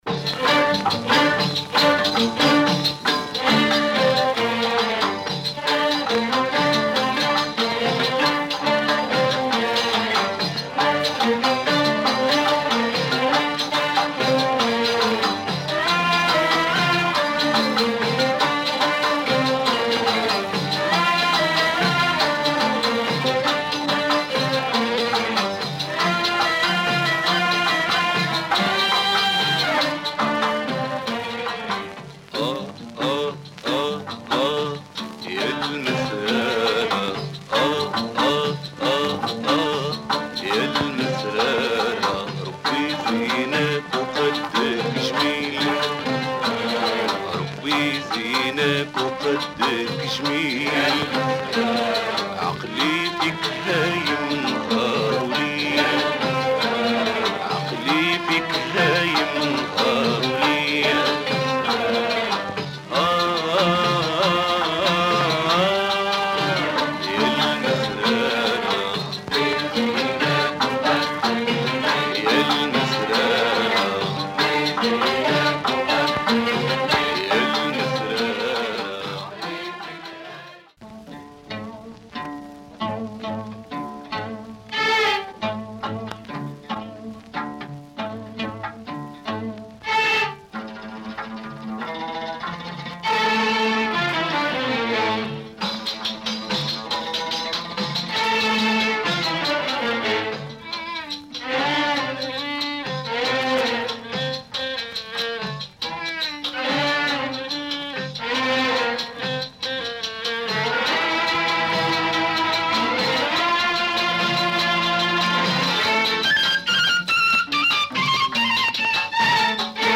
Moroccan Mizrahi